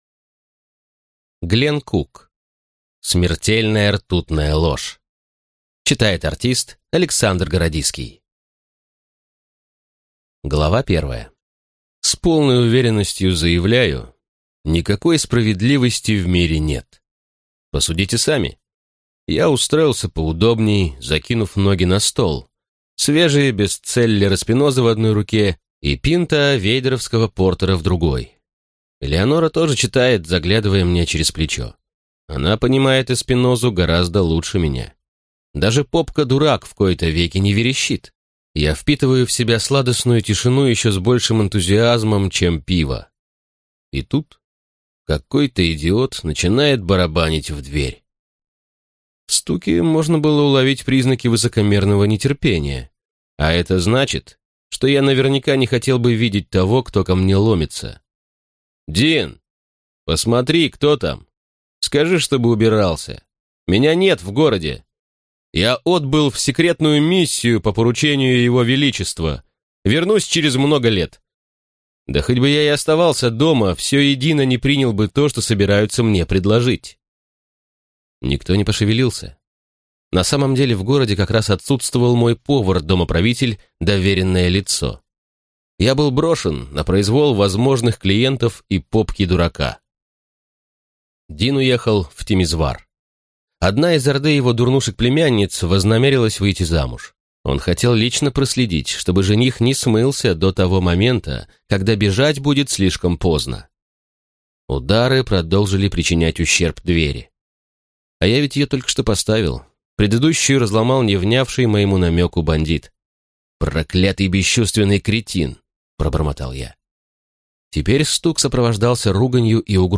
Аудиокнига Смертельная ртутная ложь | Библиотека аудиокниг